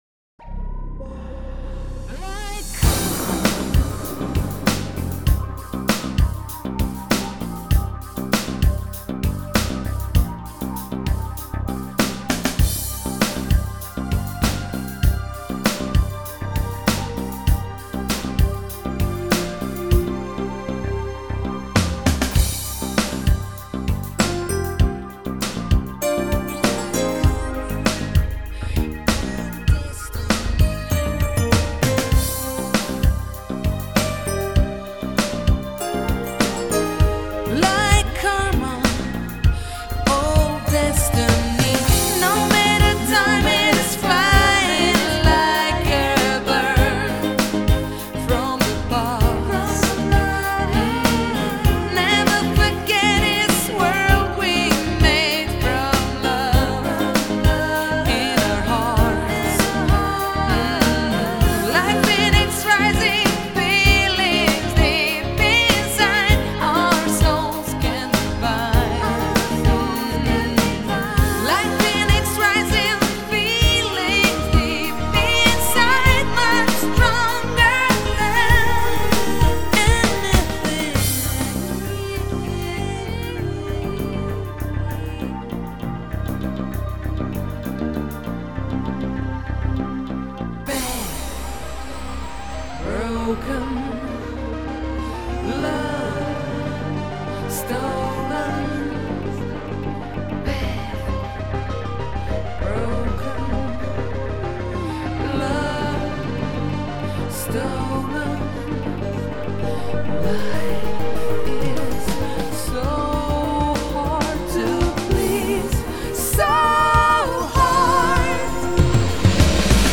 Synthpop